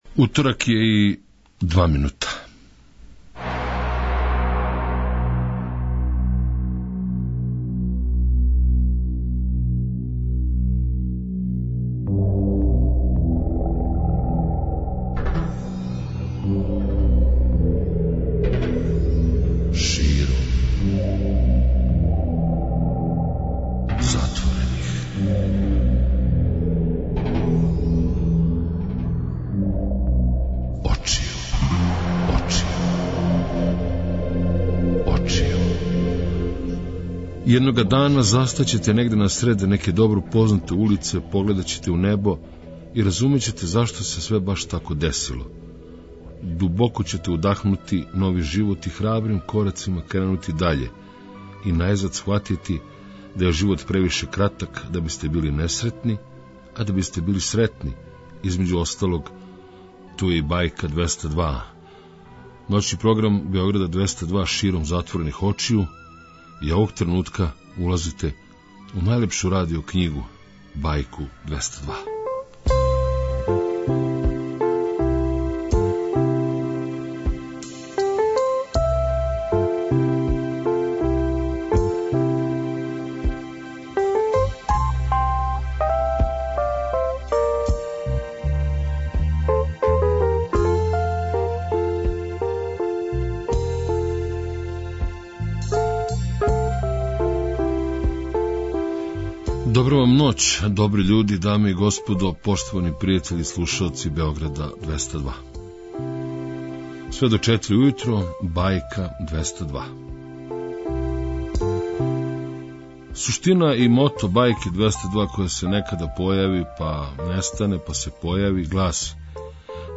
Време за опуштање, за добру музику, за бајковиту причу и девојчице и дечаке који су одавно одрасли али су у души оставили један промил за себе, за веровање да бајке постоје, макар путем радија.